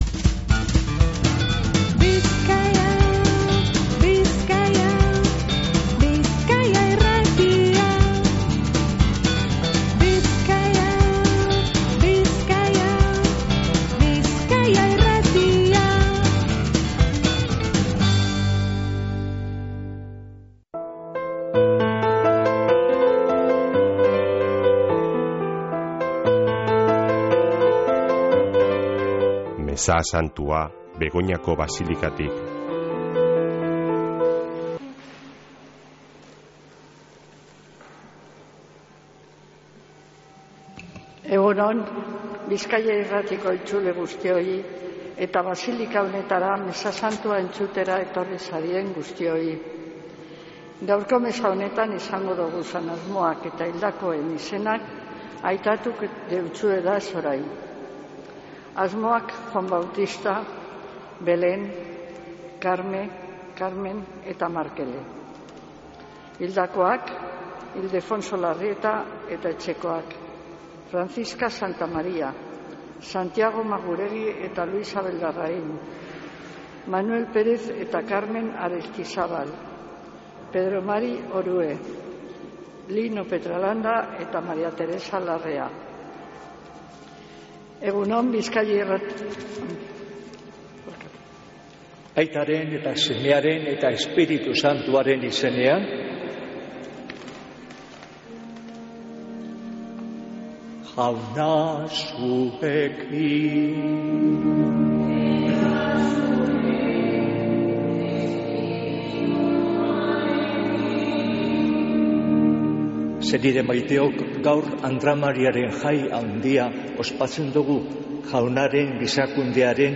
Mezea Begoñako Basilikatik | Bizkaia Irratia
Mezea (25-03-25)